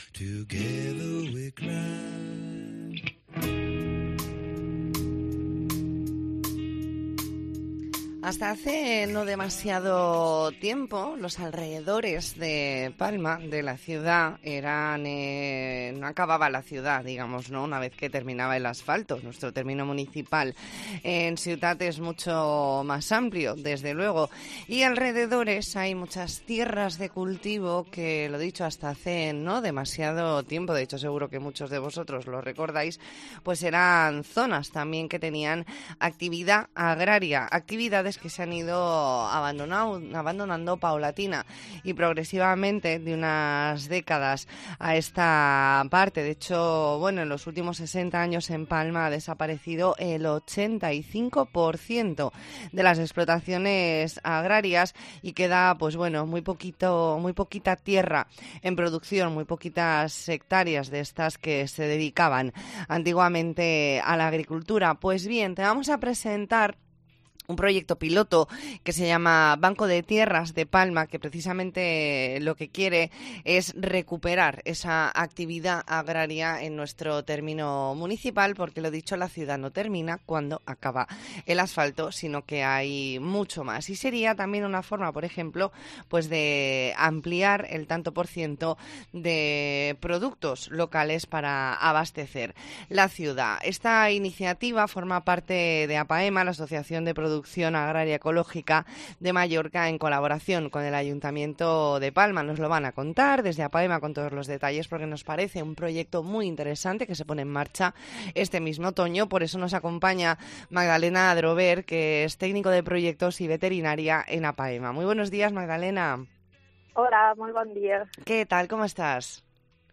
E ntrevista en La Mañana en COPE Más Mallorca, martes 25 de octubre de 2022.